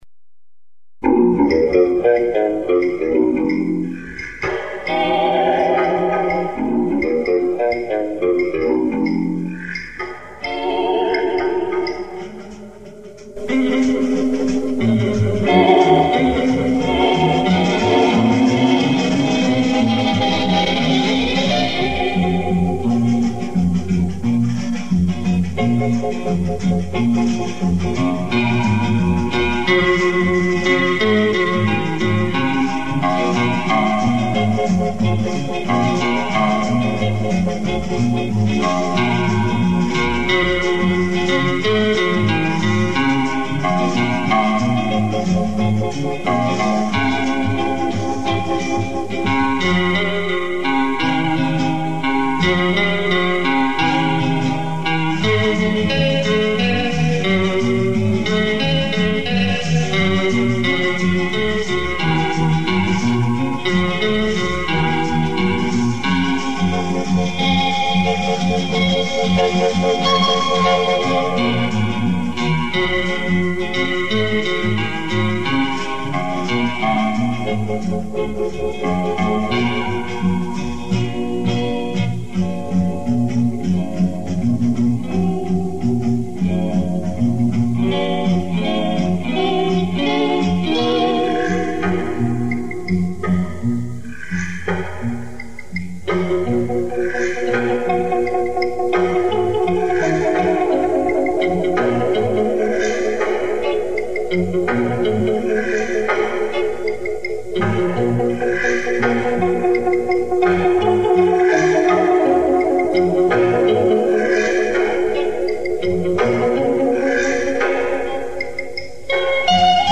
Да, на  SHADOWS  похоже.